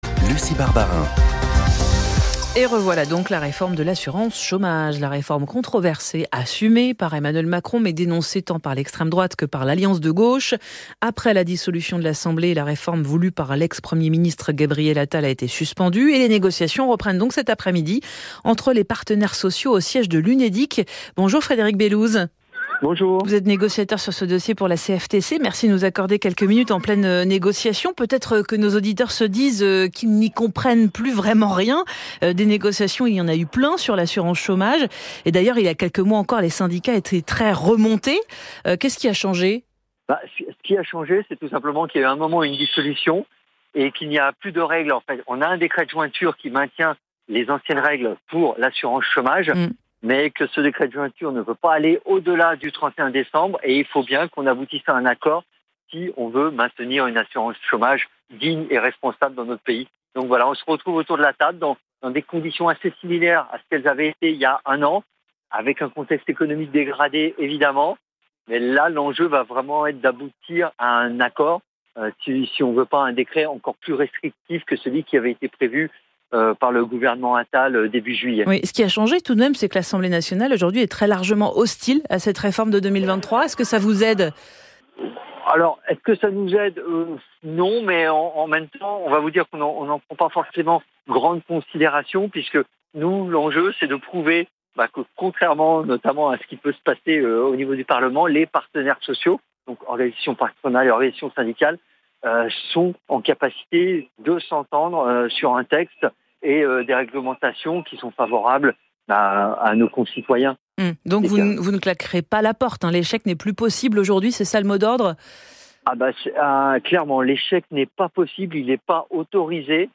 Retrouvez ci-dessous des extraits de son intervention: